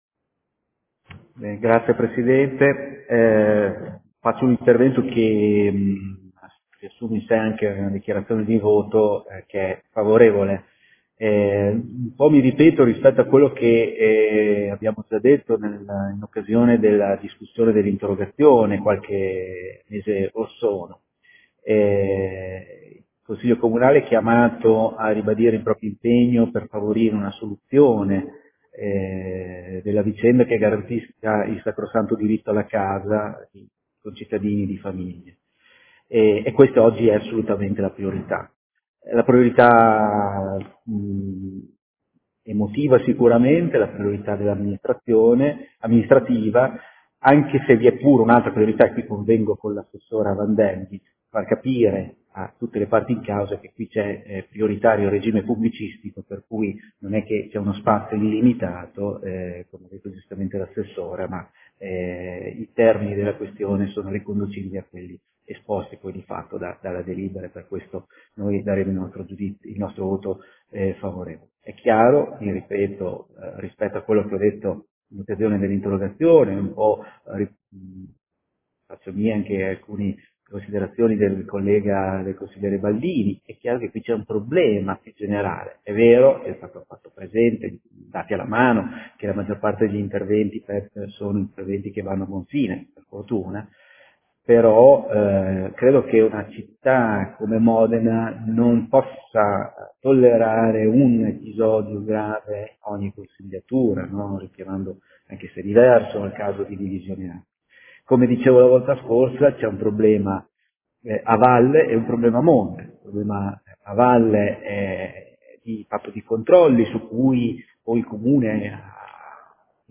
SEDUTA DEL 13/05/2020.